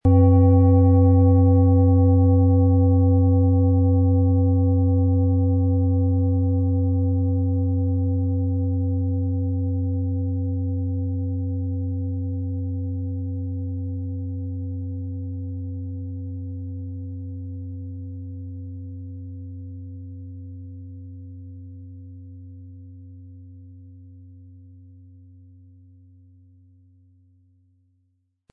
Planetenschale® Glücksplanet & Vitalisieren und gestärkt werden mit Jupiter & DNA-Ton, Ø 26,5 cm, 1800-1900 Gramm inkl. Klöppel
• Mittlerer Ton: DNA
• Höchster Ton: Delfin
Um den Originalton der Schale anzuhören, gehen Sie bitte zu unserer Klangaufnahme unter dem Produktbild.
Der passende Schlegel ist umsonst dabei, er lässt die Schale voll und harmonisch tönen.
PlanetentöneJupiter & DNA & Delfin (Höchster Ton)
MaterialBronze